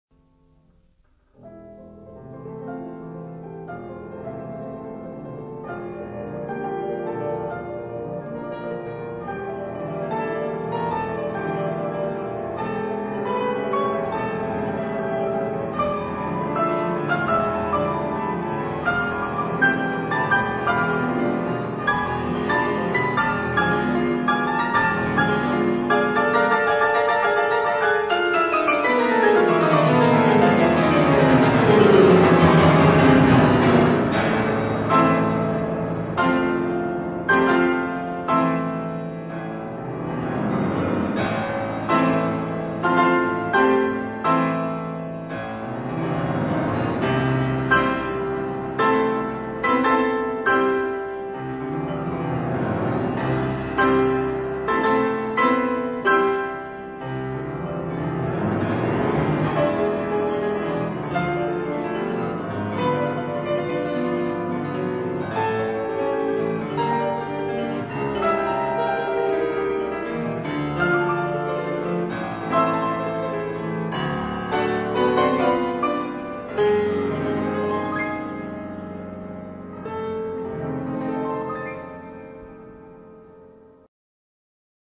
Concerto pour piano et orchestre en La mineur